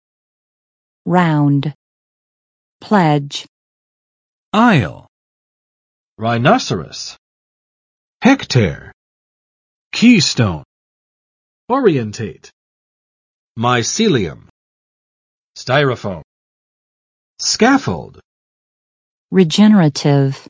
[raʊnd] v. 完成；使圆满结束